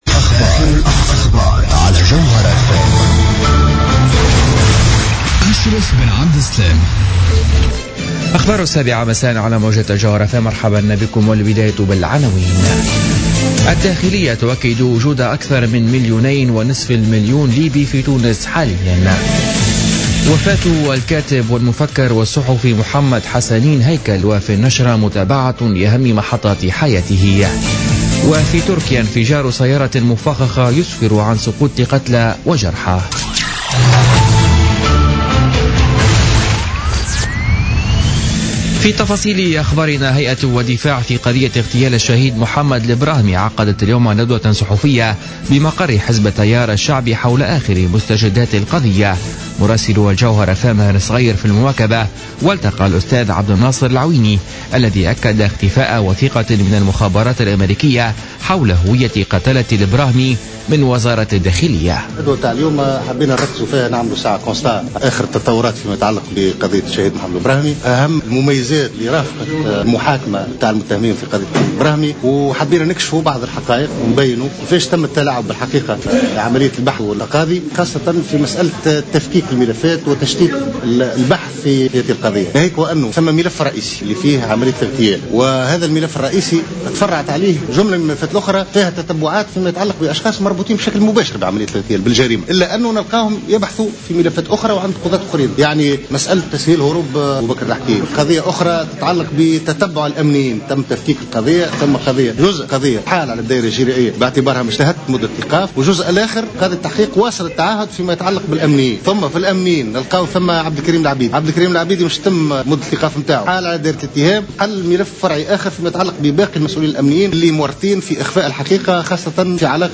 نشرة أخبار السابعة مساء ليوم الأربعاء 17 فيفري 2016